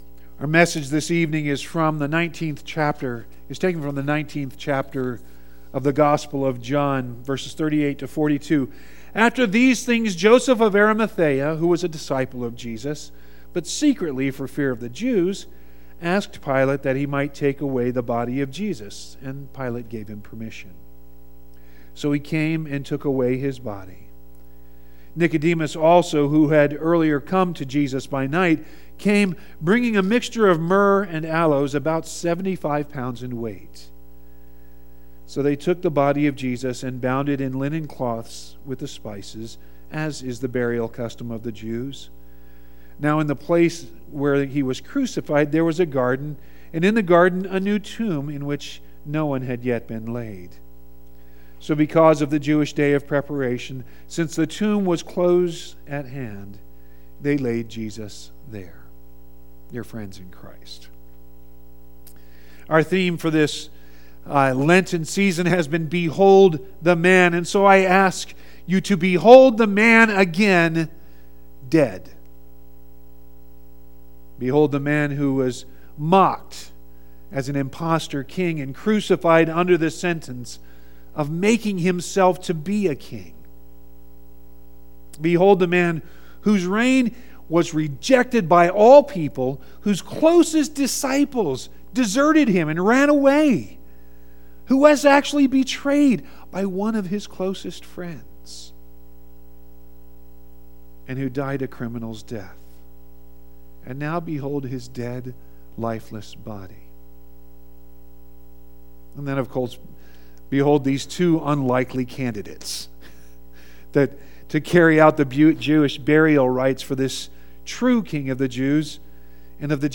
Easter Vigil 4.20.19
You can also download the sermon directly HERE, or get all the sermons on your phone by subscribing to our Podcast HERE.